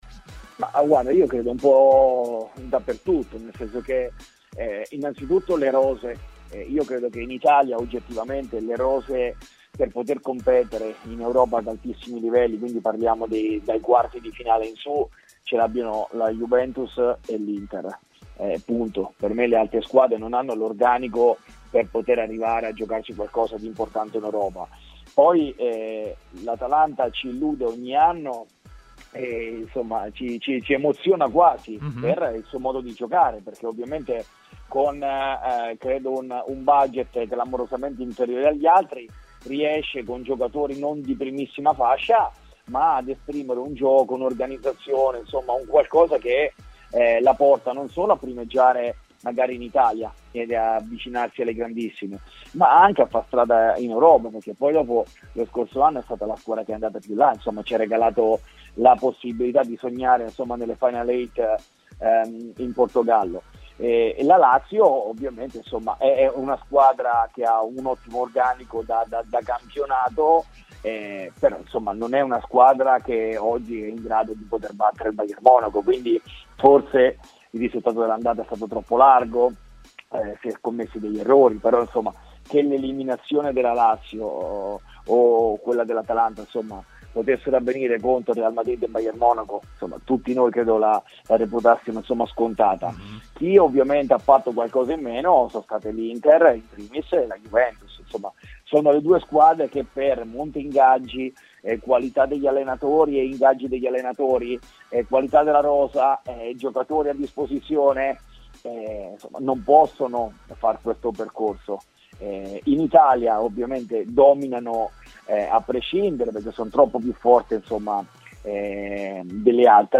L'allenatore Cristian Bucchi, intervenuto ai microfoni di TMW Radio, ha parlato del gap tra squadra italiane e le altre europee.